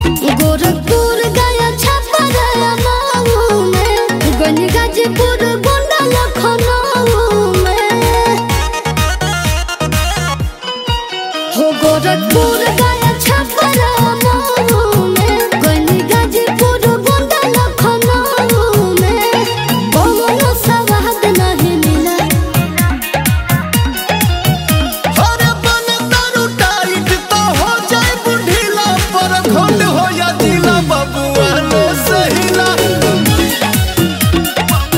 Perfect for those who love desi swag with heavy beats.